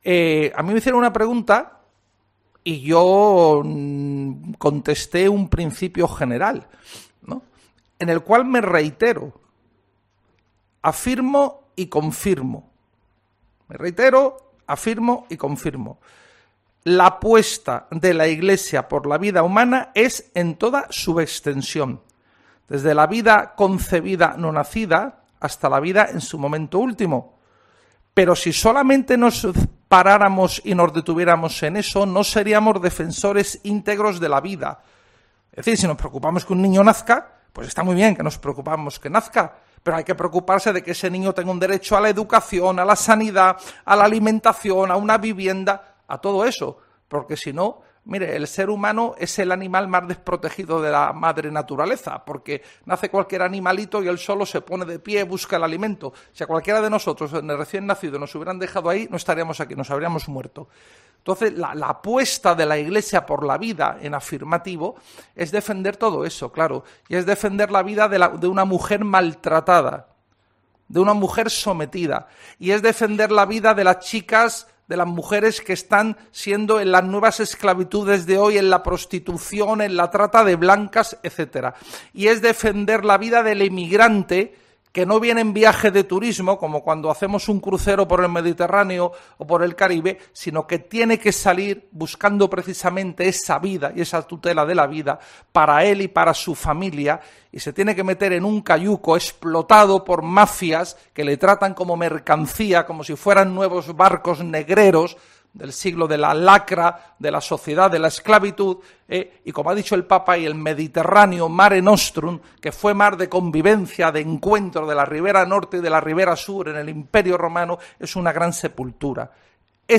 Reitero, afirmo y confirmo: la apuesta de la Iglesia por la vida humana es en toda su extensión, desde la vida concebida no nacida hasta la vida en su momento último”: así de claro se ha mostrado el secretario general de la CEE, Francisco César García Magán, durante la rueda de prensa en la cual ha informado sobre los trabajos de la Comisión Permanente.